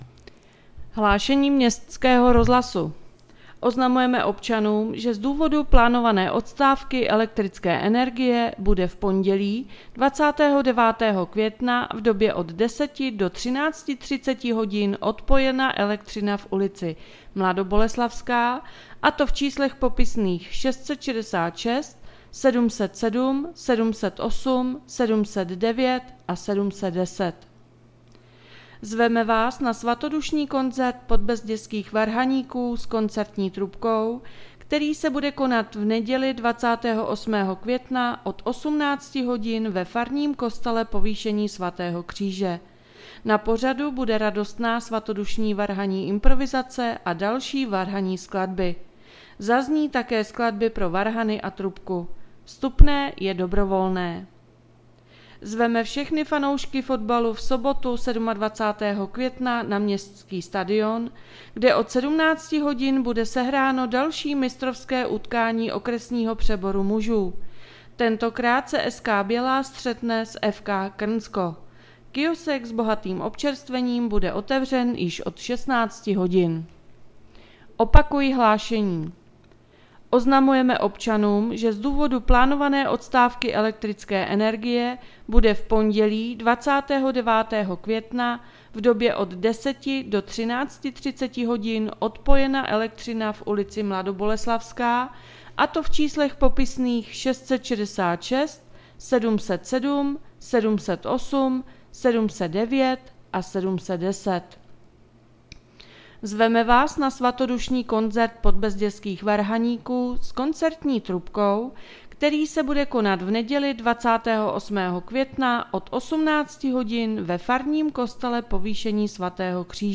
Hlášení městského rozhlasu 26.5.2023